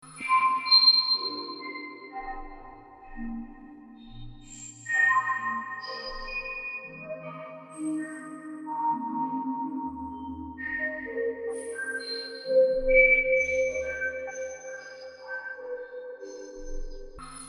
Nature » Forest birds 2
描述：Fieldrecording of birds singing in a forest. Recorded with Zoom H1
标签： birdsong bird ambience ambient forest birds ambiance spring nature fieldrecording
声道立体声